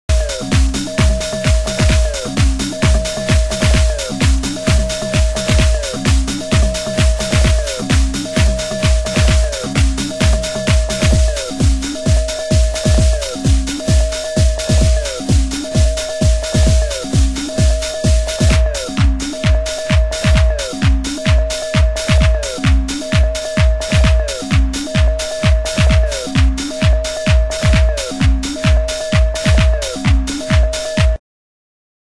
シカゴテクノ・アンセム！